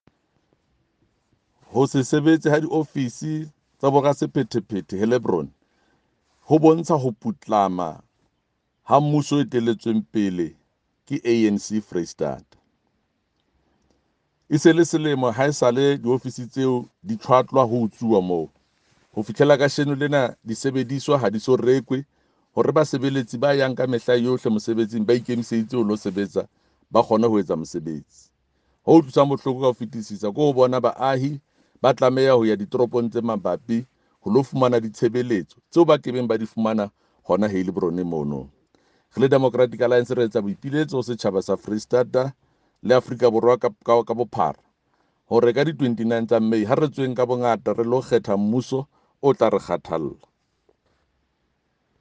Sesotho soundbites by Jafta Mokoena MPL